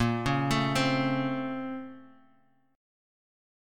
A#mM9 Chord
Listen to A#mM9 strummed